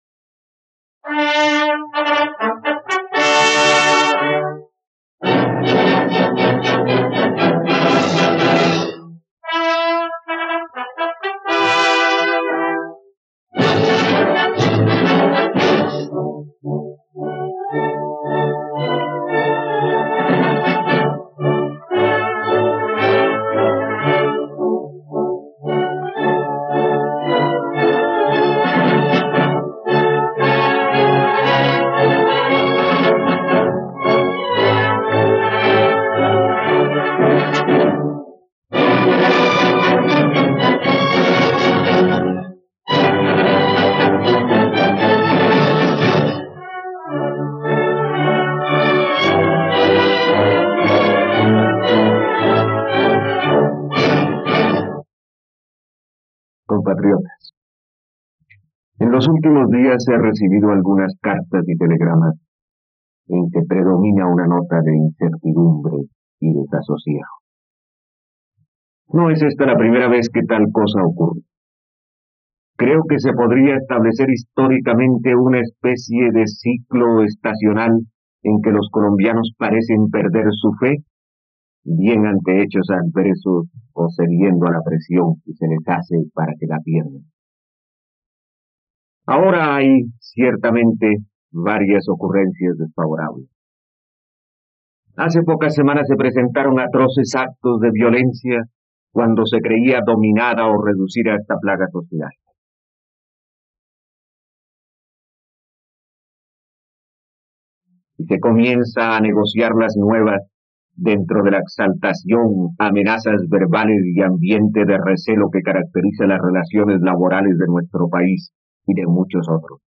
..Escucha ahora el discurso de Alberto Lleras Camargo sobre los problemas de orden público, el 9 de marzo de 1961, en la plataforma de streaming RTVCPlay.